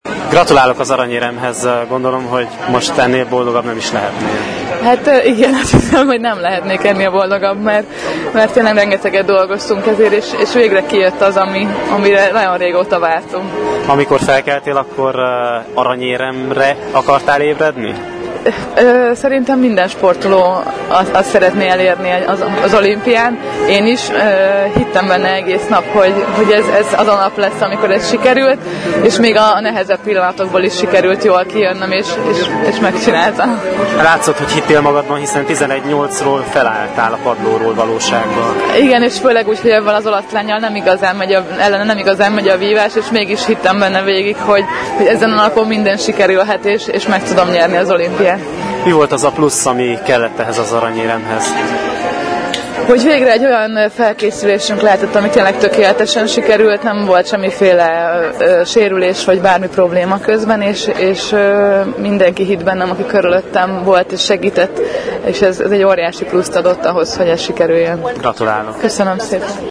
Szász Emese párbajtőröző az olasz Rossella Fiamingot győzte le, miután 4 tus hátrányból fordítani tudott. A magyar vívó a díjkiosztás után nyilatkozott a Marosvásárhelyi Rádiónak: